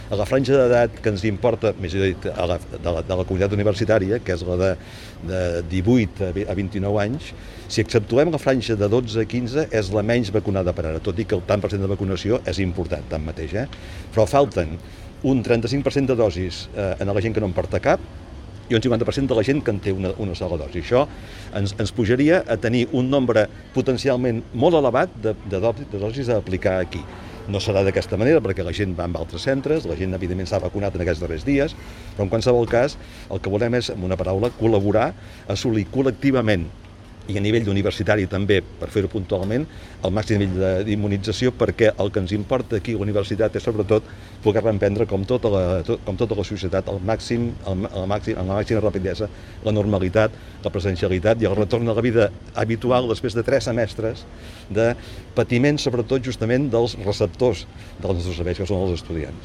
Declaracions: